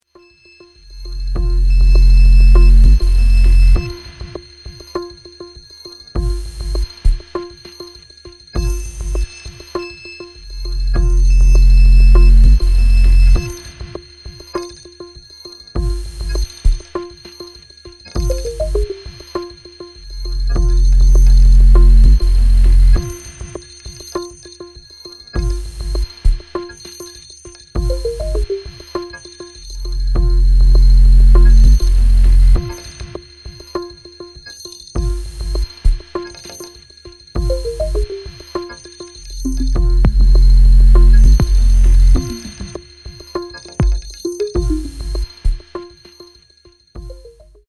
German electronic musician